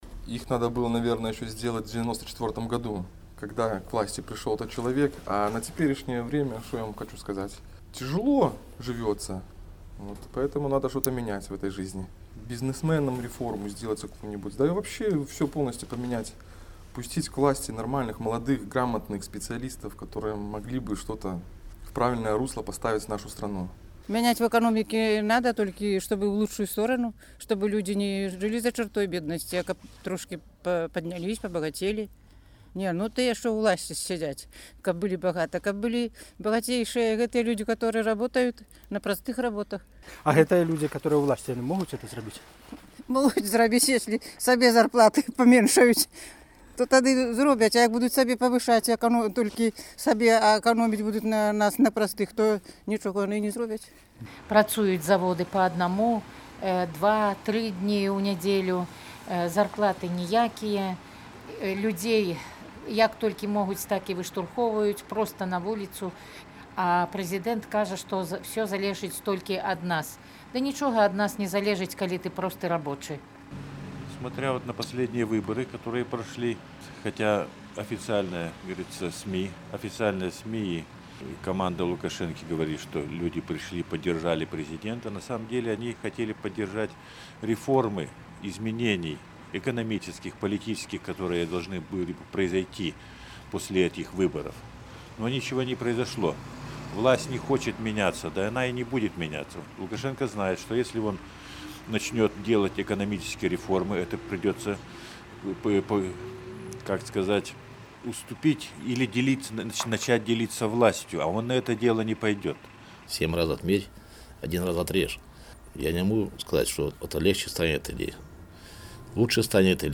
На такія пытаньні карэспандэнта Свабоды адказвалі сёньня жыхары Берасьця.